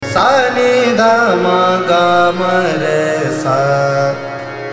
ThaatKhammaj
AvarohaS’ n D m G m R S